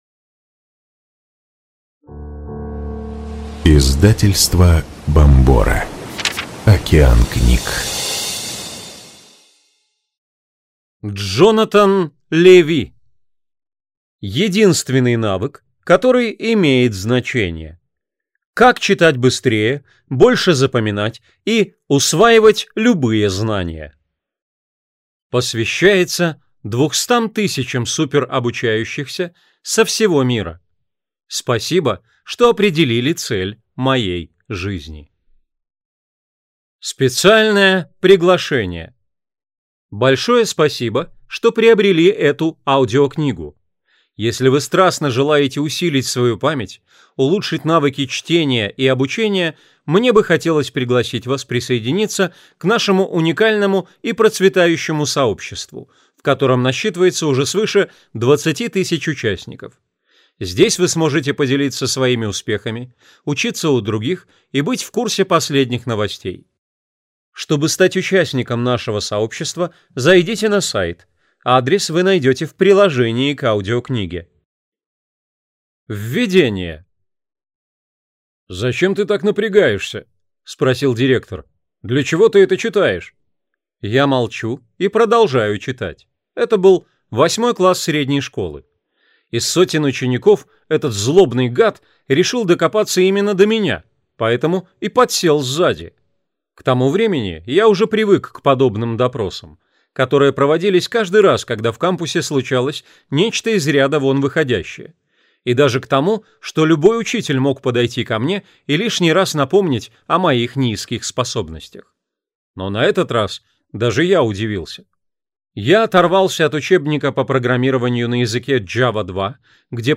Аудиокнига Единственный навык, который имеет значение. Как читать быстрее, больше запоминать и усваивать любые знания | Библиотека аудиокниг